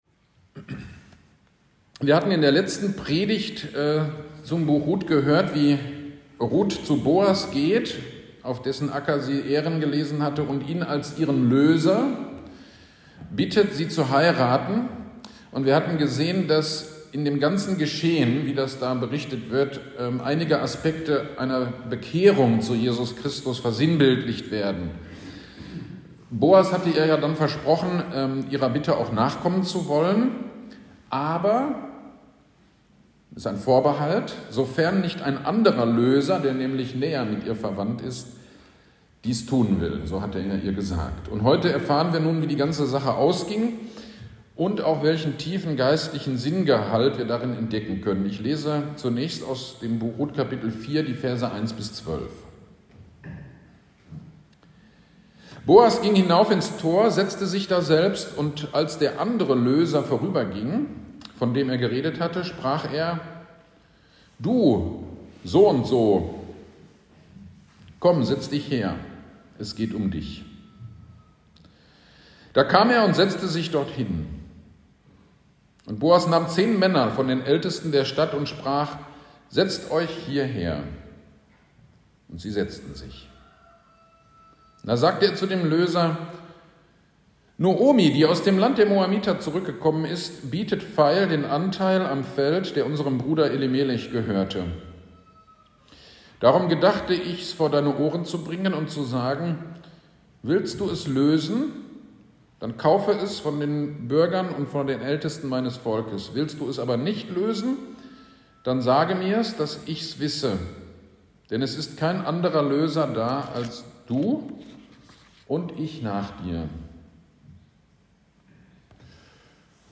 GD am 25.06.23 Predigt zu Rut 4